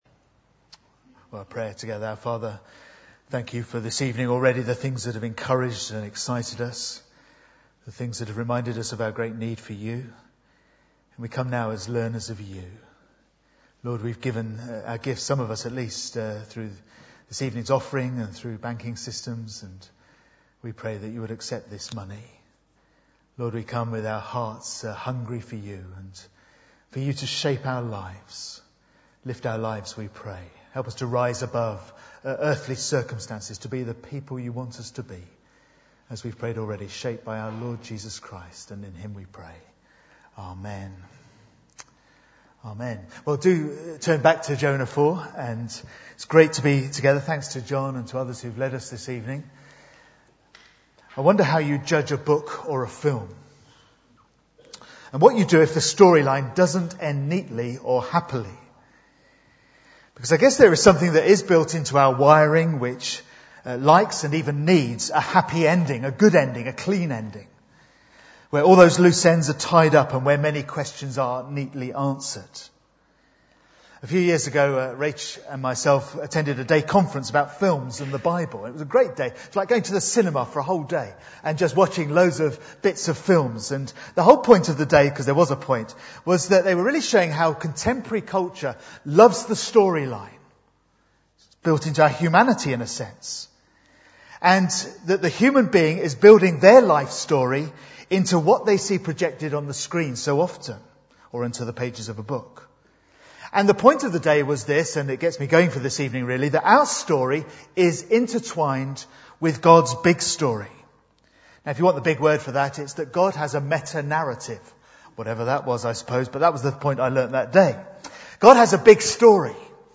Bible Text: Jonah 4:1-11 | Preacher